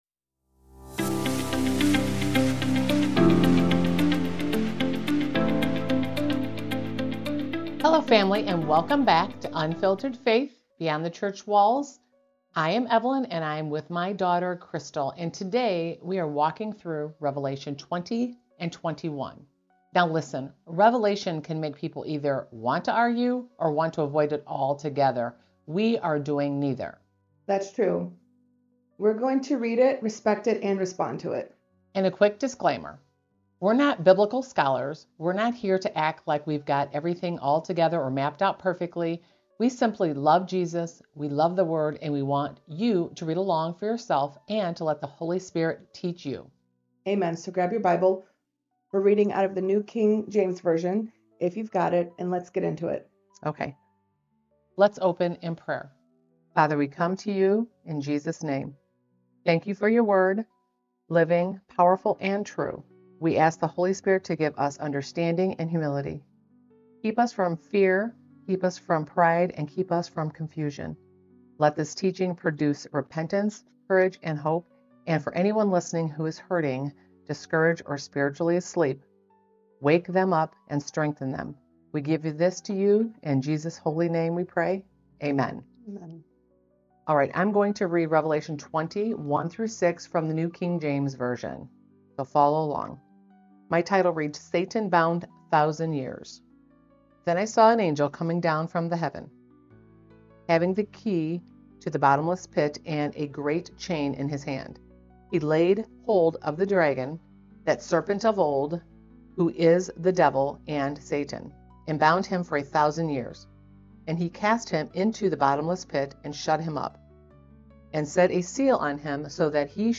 In this episode, we focus more on reading and teaching (less discussion) as we walk through Revelation 20–21 in the NKJV.